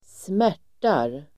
Uttal: [²sm'är_t:ar]